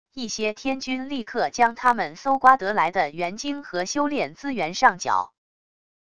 一些天君立刻将他们搜刮得来的元晶和修炼资源上缴wav音频生成系统WAV Audio Player